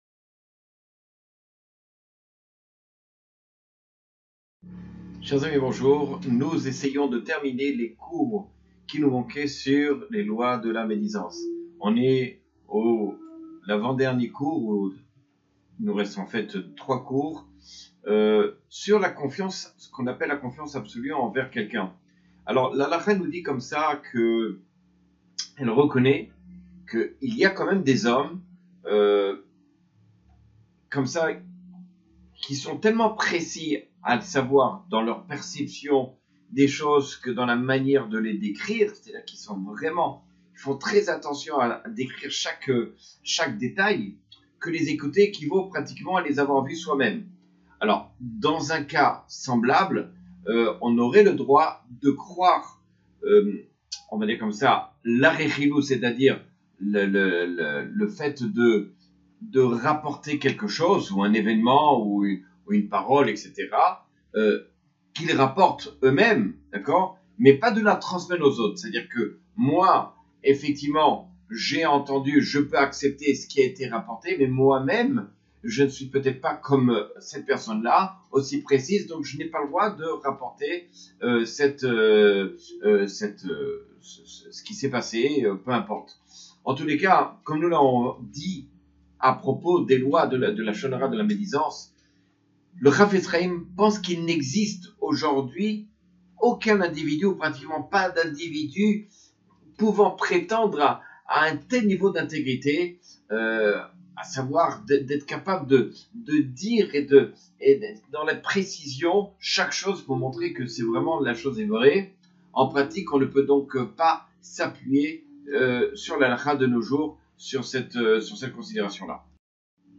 Cours 175 sur les lois de la médisance, le lashon hara.